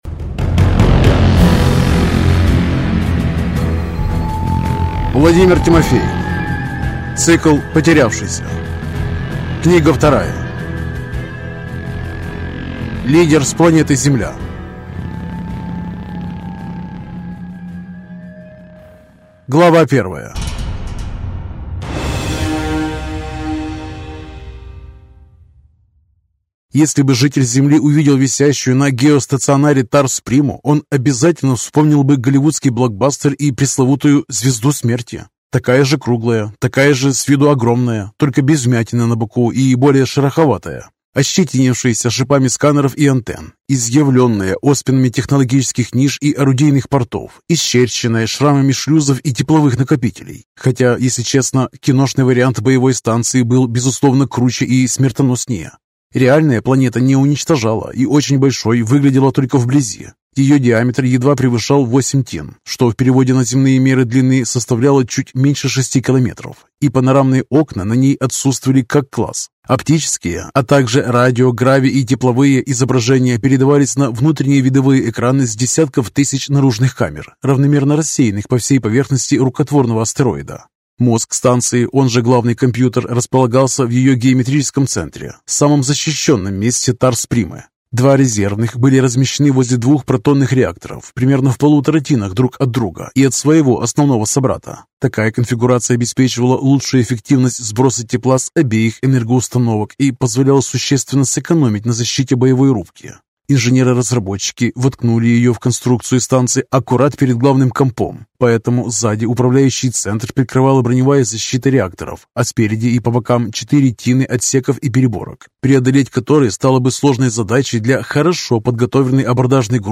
Аудиокнига Лидер с планеты Земля | Библиотека аудиокниг